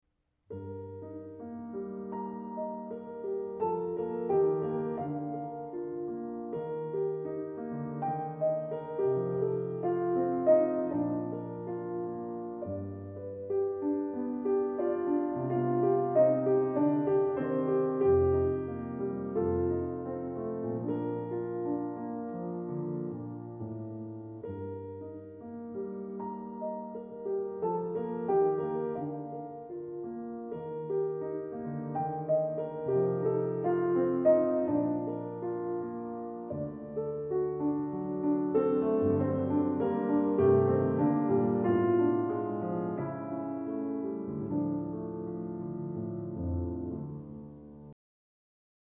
Klavier
Solo: Andante B-Dur“ aus „5 Klavierstücke op.3” von Richard Strauss